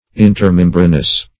intermembranous - definition of intermembranous - synonyms, pronunciation, spelling from Free Dictionary
Search Result for " intermembranous" : The Collaborative International Dictionary of English v.0.48: Intermembranous \In`ter*mem"bra*nous\, a. (Anat.)